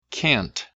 単語だと“ can ”’tの「トゥ」がハッキリ聞こえますよね。